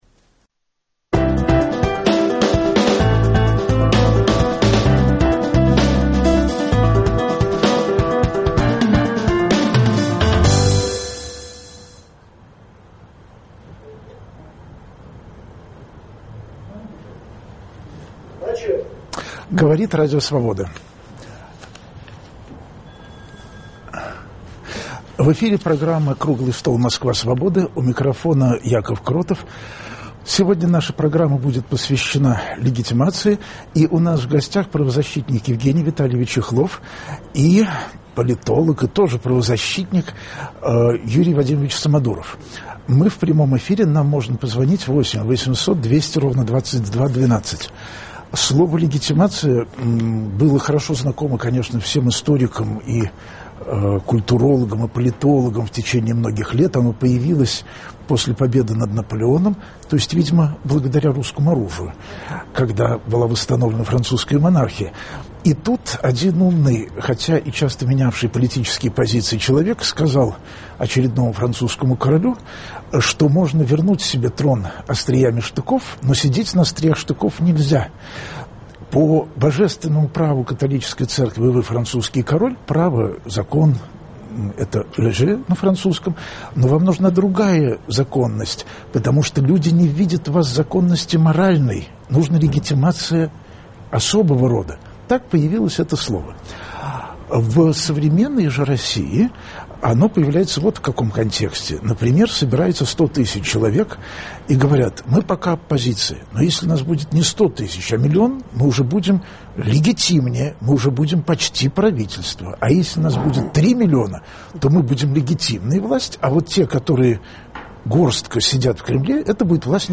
Круглый стол: Москва Свободы